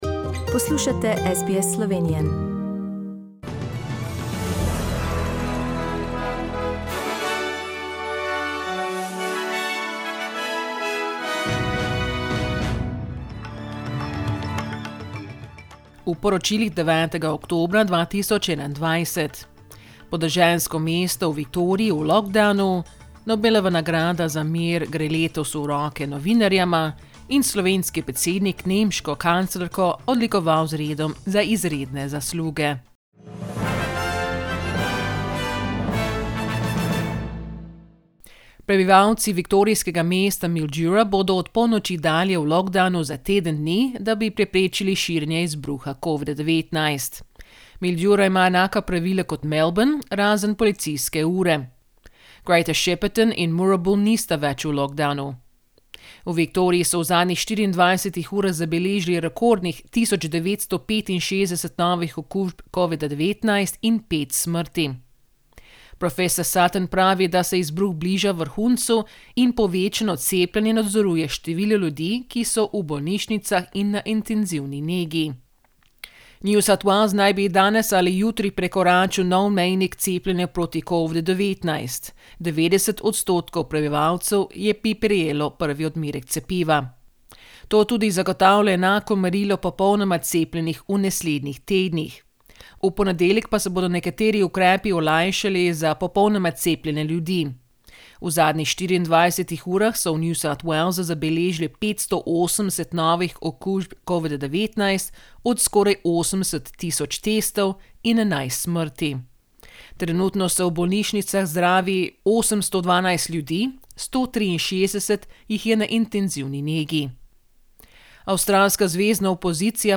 SBS News in Slovenian - 9th October, 2021
Listen to the latest news headlines in Australia from SBS Slovenian radio.